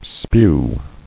[spew]